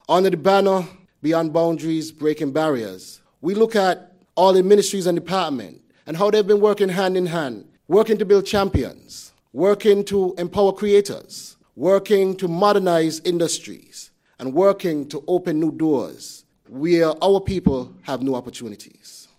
Ministry of Agriculture-St. Kitts hosts Quarterly Press Conference